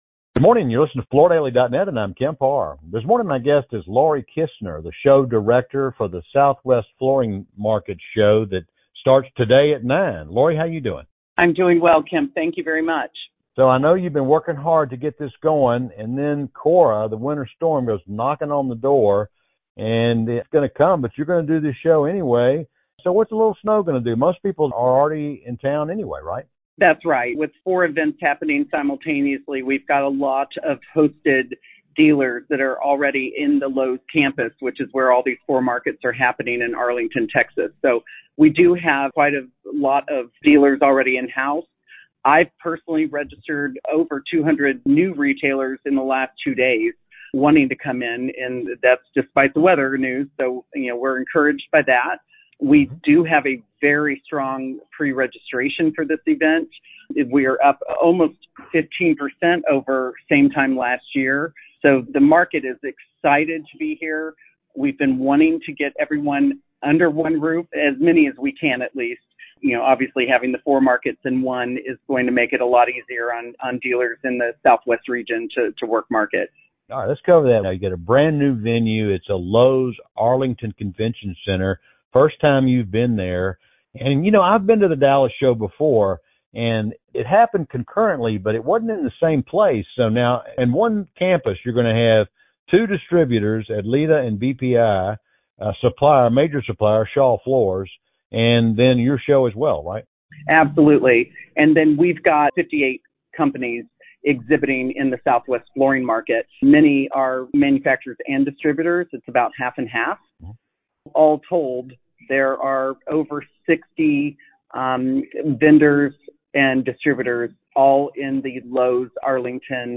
This year, Shaw Industries, BPI, Adleta and the Southwest Flooring Market are in one location. Listen to the interview for more details.